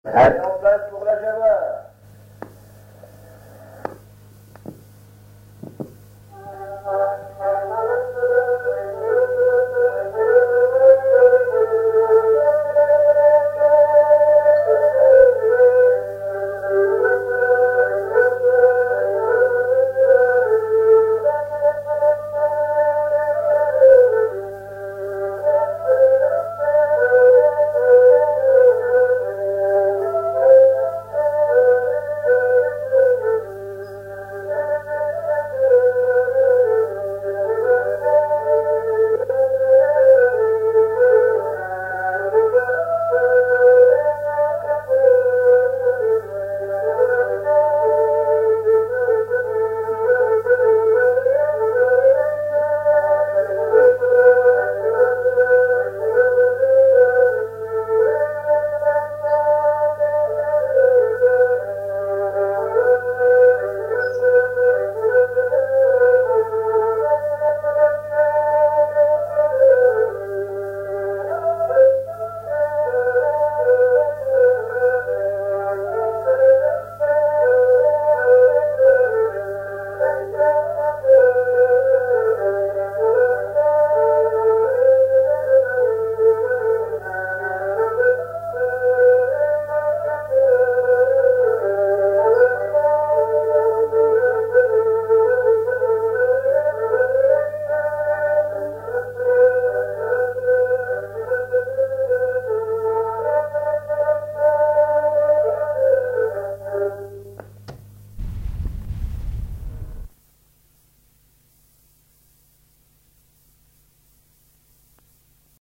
pièce repiquée d'un 78t
Genre brève
Pièce musicale inédite